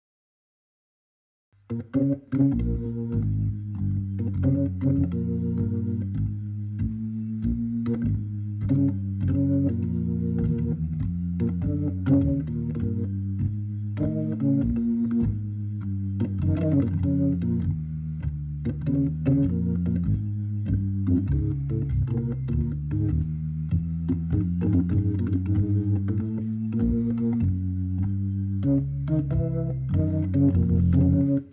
le tout avec l'overdrive a tout casser :)